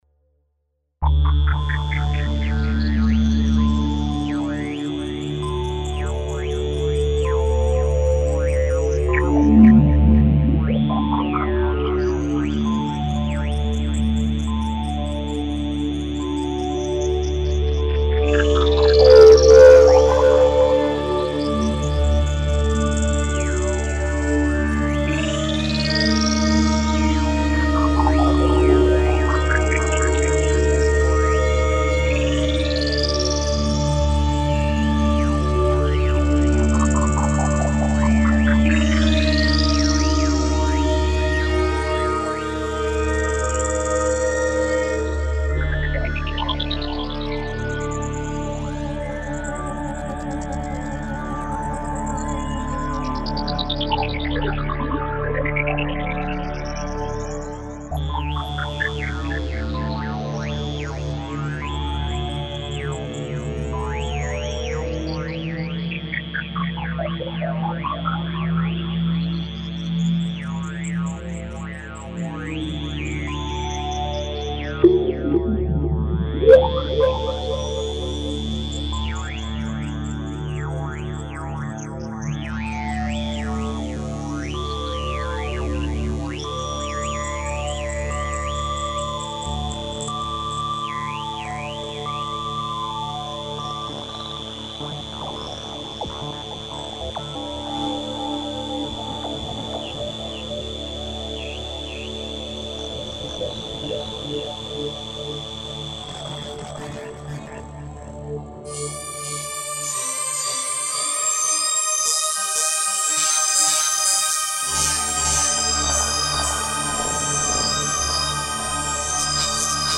DETAILS SOUND swirling, shimering and evolving wave pads and FX SOUND schwebende und bewegliche Flächen und Effekte.
Plasmatron_on_Fizmo.mp3